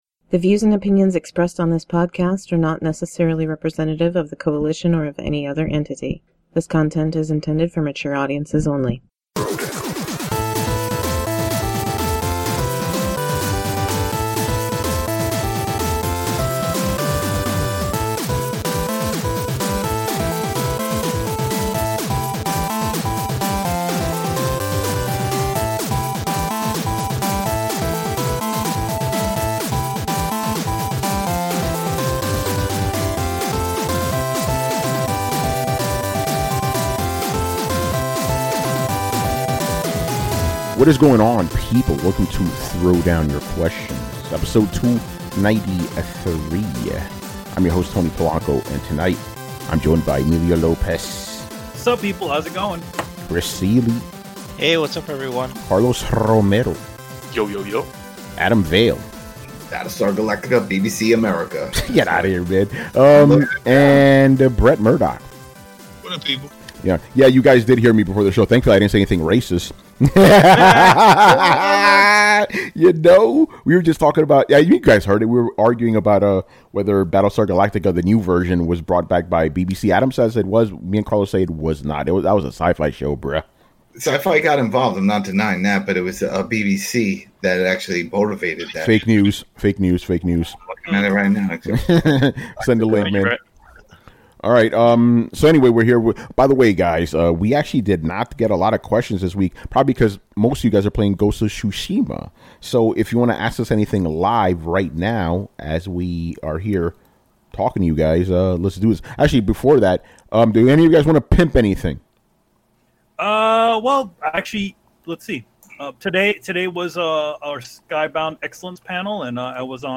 On this show, we answer all of your Video Game related questions.